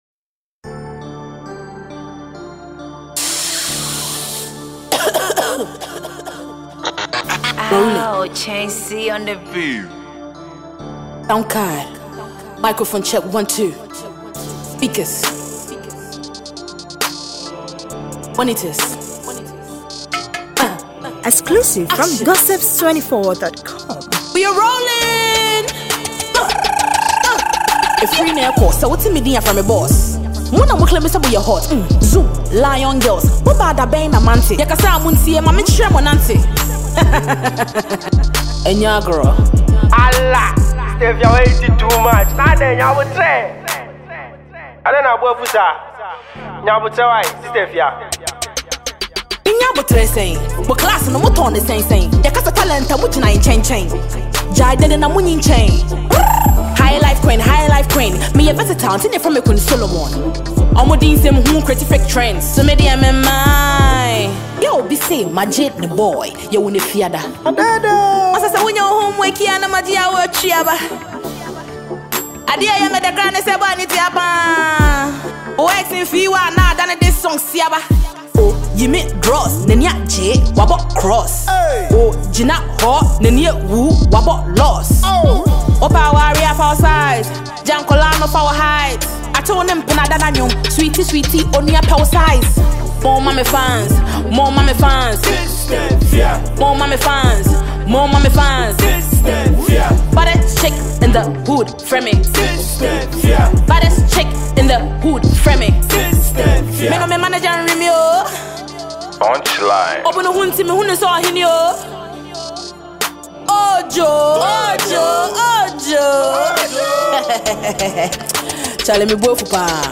hip-hop tune